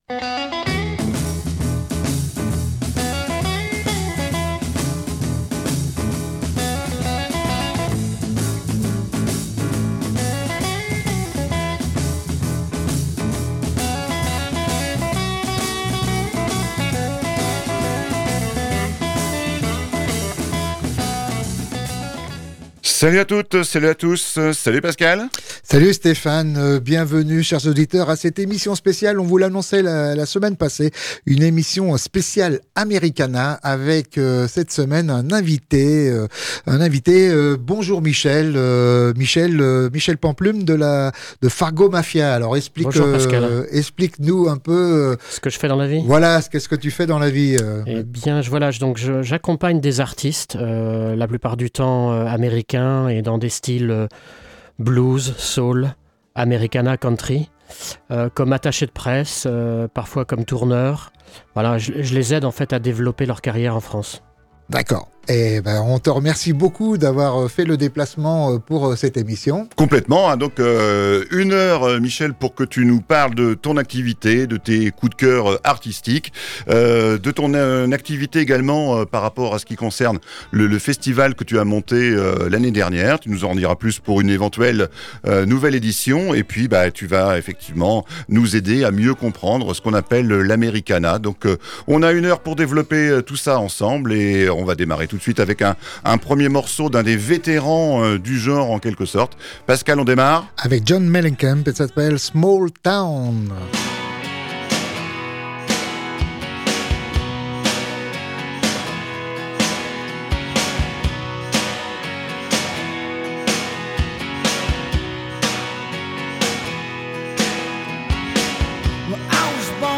est venu dans les studios de Radio Alpa